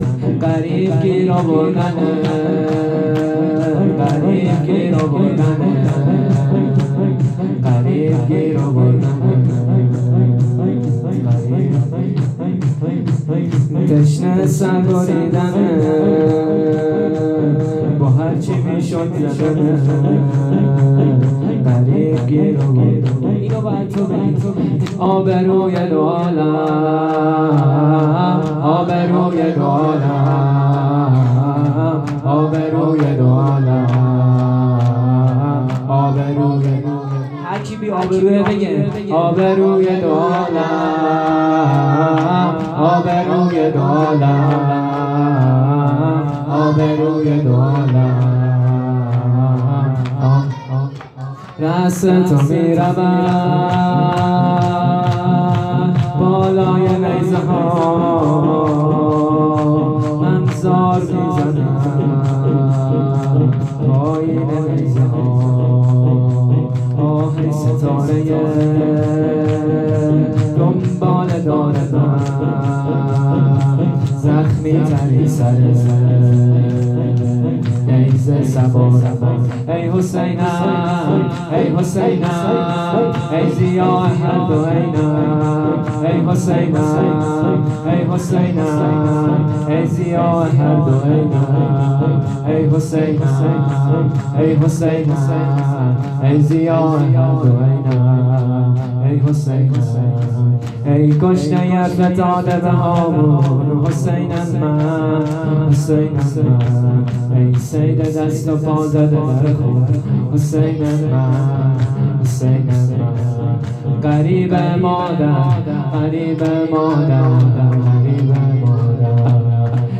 خیمه گاه - شجره طیبه صالحین - غریب گیر آوردنت _ شور _ سه شب نوکری